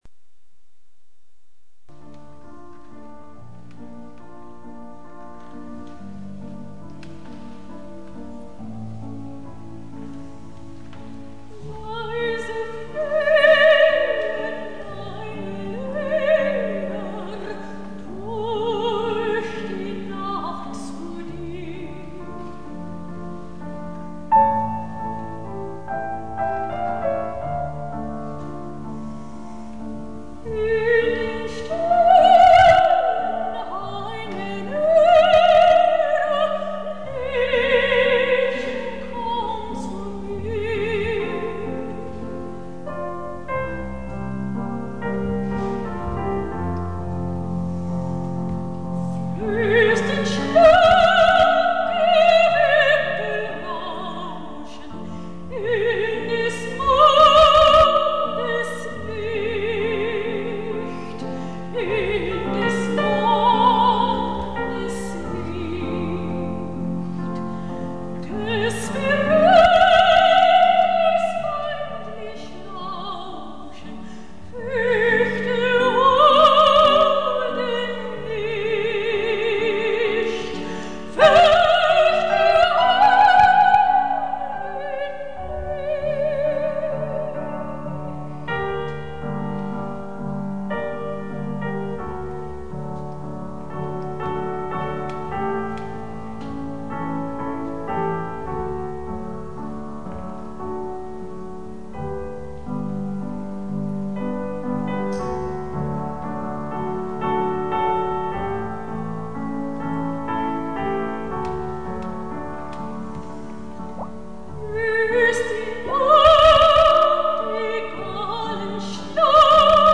RECITAL  PRESTIGO
soprano
au piano